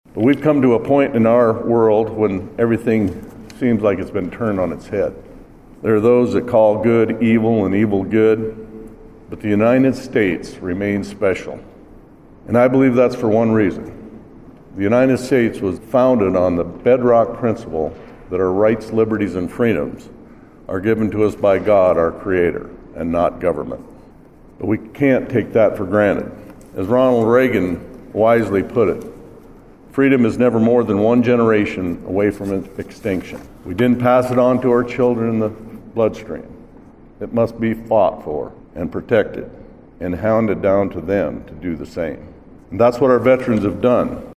PIERRE, S.D.(DRGNews)-The United States Marine Corps turned 250 years old Monday and South Dakota marked the occasion with a ceremony and official cake cutting in Pierre.